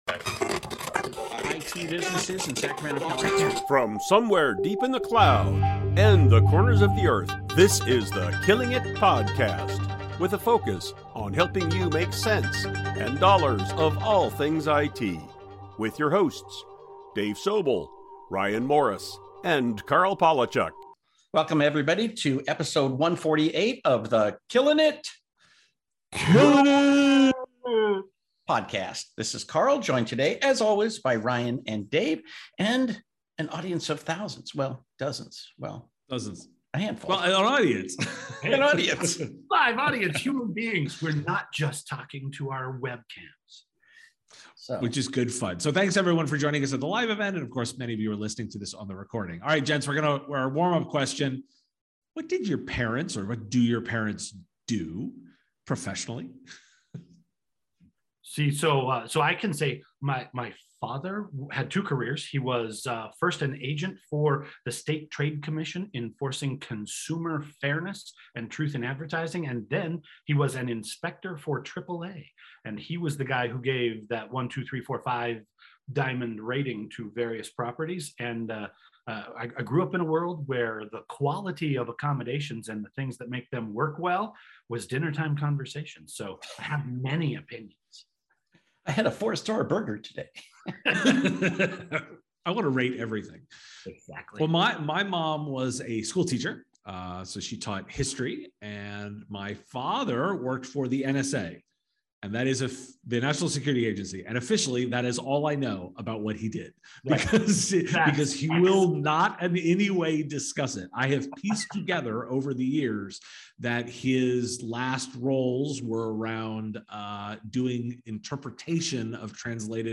Recorded as "Killing IT Live" - and then we picked out these three topics.